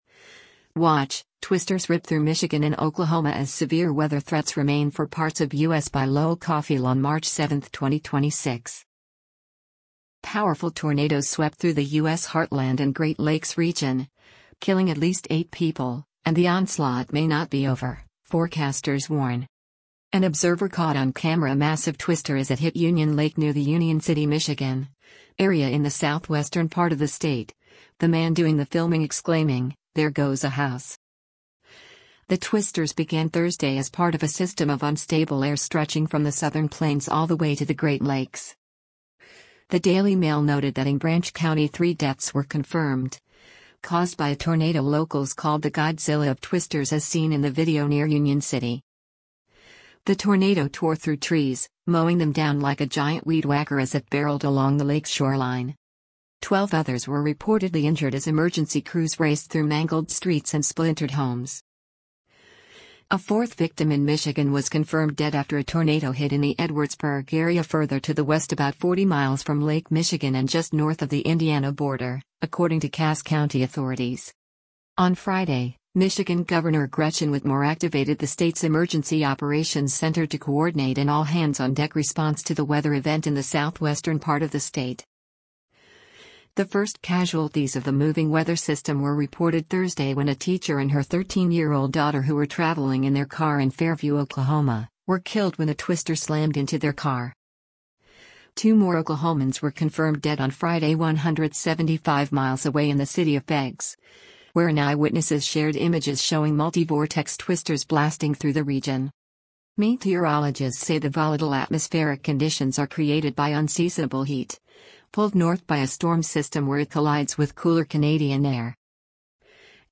An observer caught on camera a massive twister as it hit Union Lake near the Union City, Michigan, area in the southwestern part of the state, the man doing the filming exclaiming, “There goes a house!”
The tornado tore through trees, mowing them down like a giant weed whacker as it barreled along the lake’s shoreline.